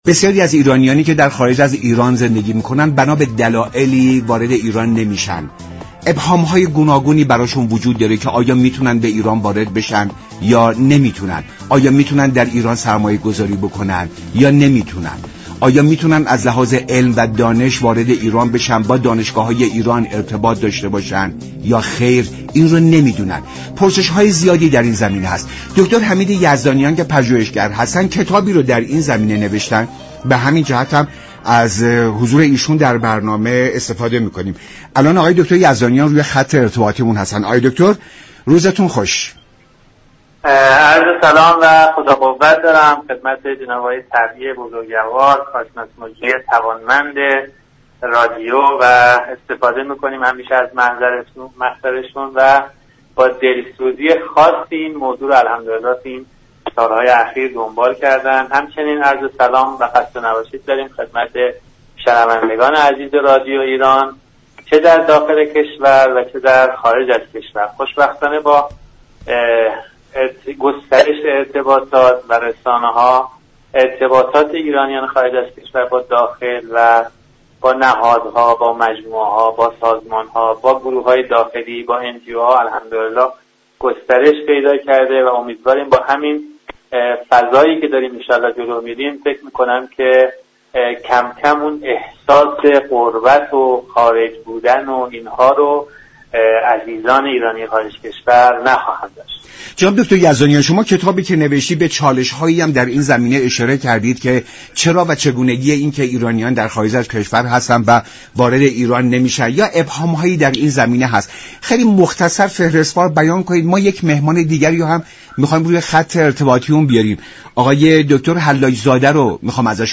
در ادامه این گفت و گوی رادیویی